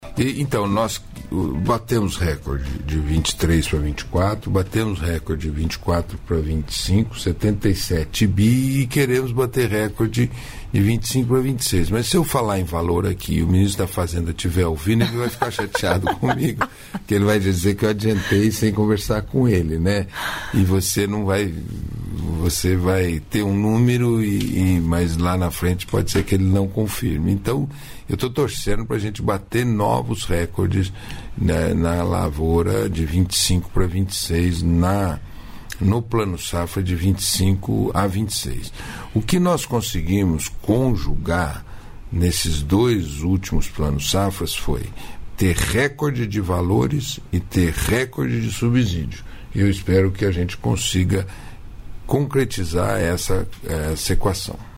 Trecho da participação do ministro do Desenvolvimento Agrário e Agricultura Familiar do Brasil, Paulo Teixeira, no programa "Bom Dia, Ministro" desta terça-feira (10), nos estúdios da EBC em Brasília (DF).